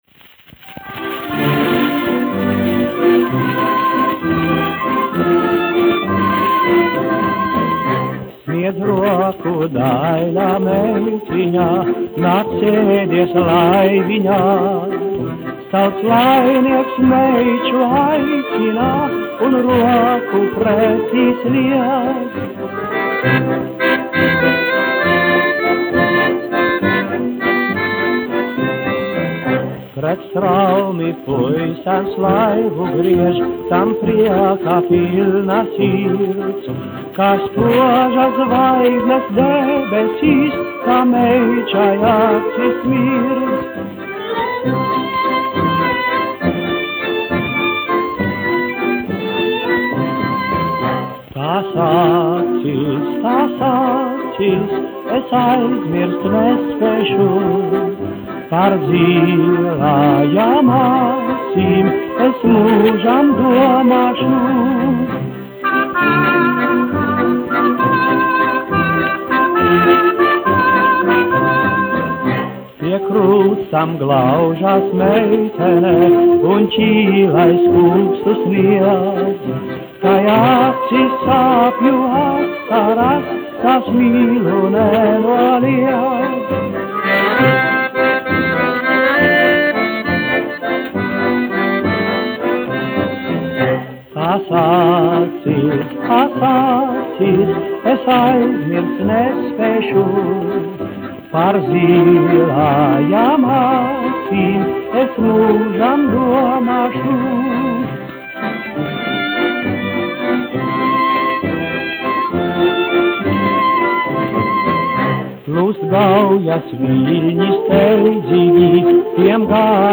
1 skpl. : analogs, 78 apgr/min, mono ; 25 cm
Populārā mūzika -- Latvija
Latvijas vēsturiskie šellaka skaņuplašu ieraksti (Kolekcija)